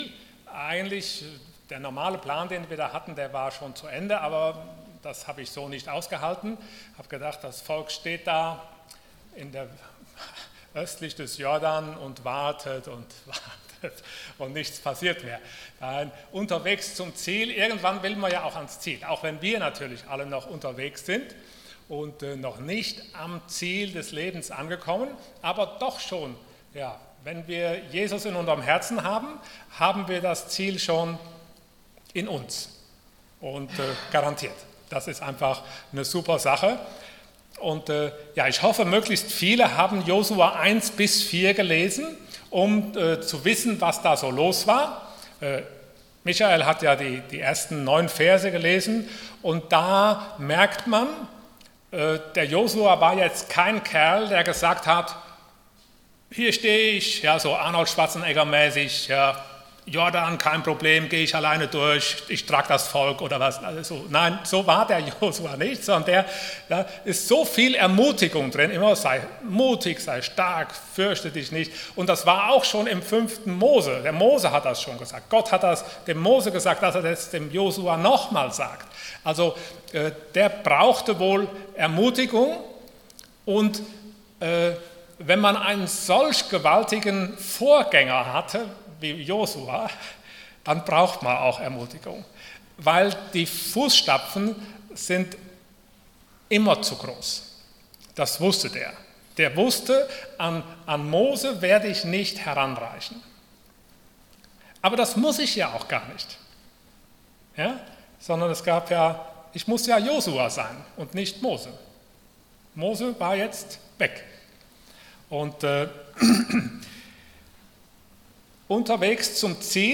Passage: Josua 3,1-4,24 Dienstart: Sonntag Morgen